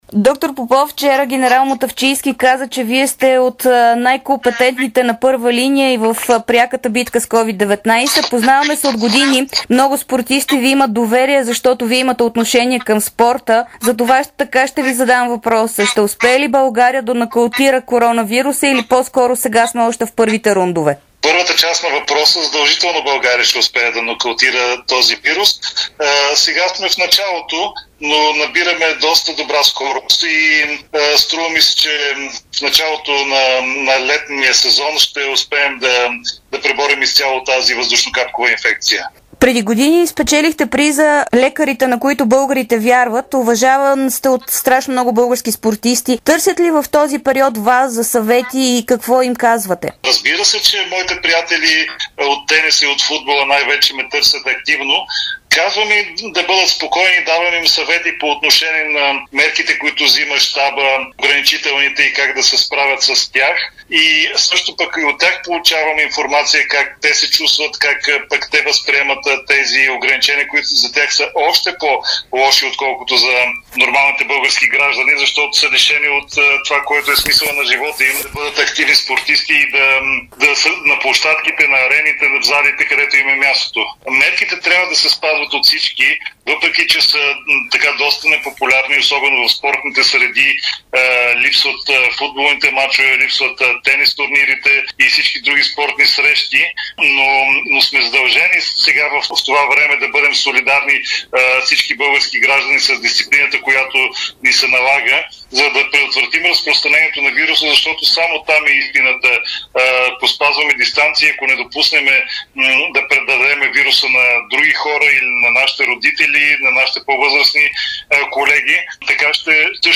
интервю за dsport и Дарик радио